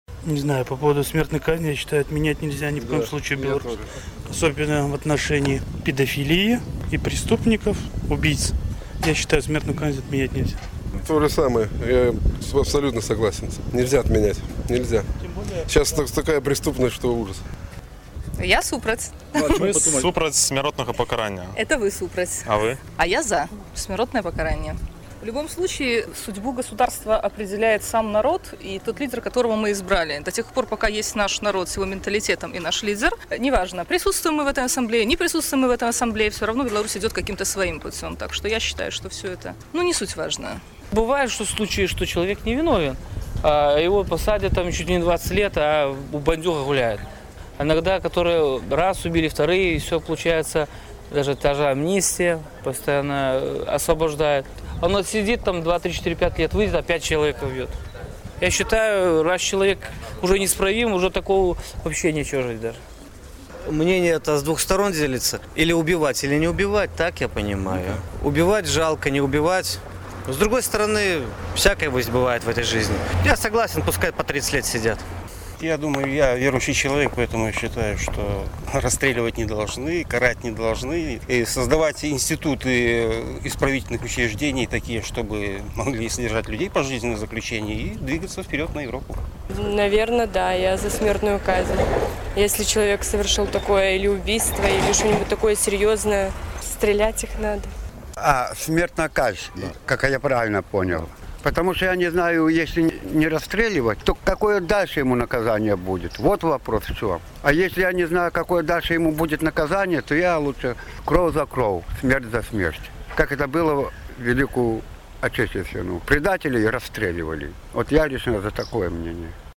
Vox-populi у Горадні